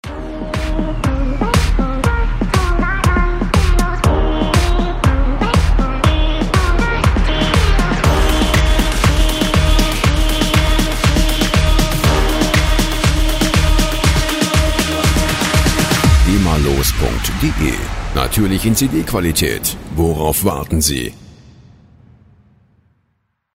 Show Opener
Musikstil: Uplifting EDM
Tempo: 120 bpm